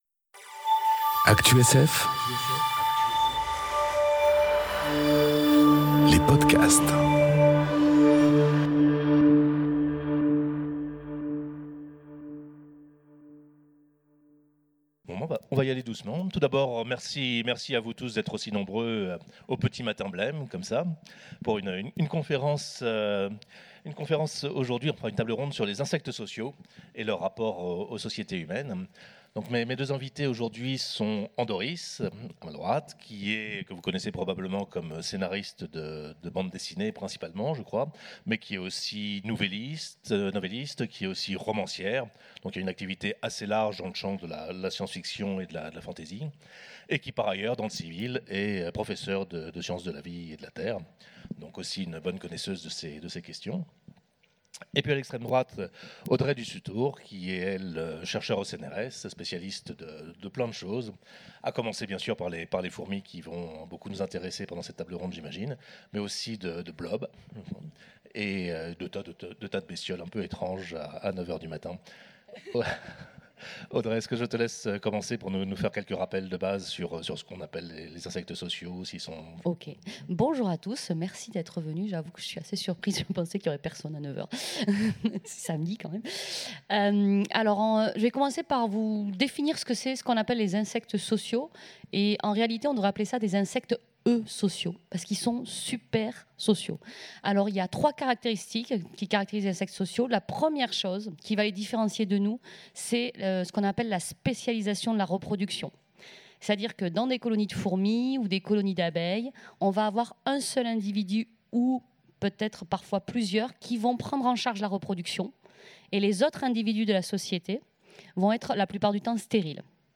Conférence Les insectes sociaux, un bon modèle de société ? enregistrée aux Utopiales 2018